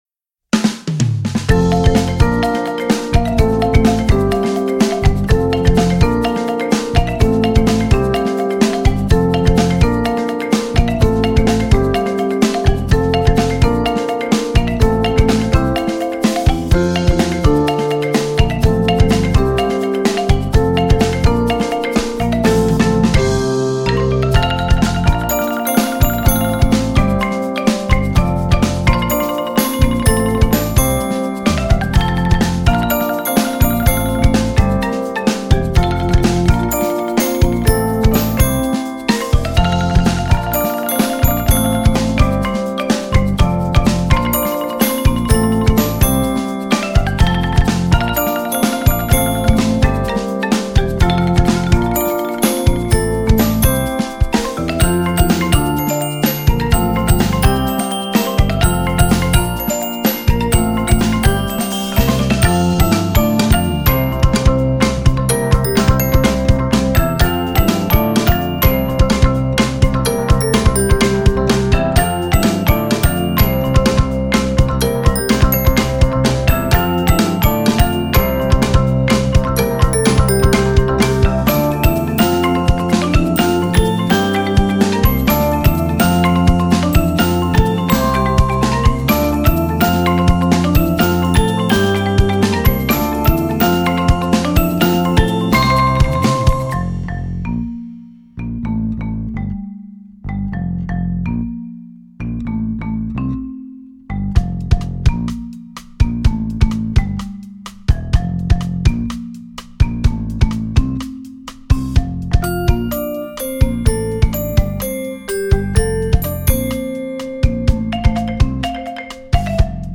Voicing: Percussion Ensemble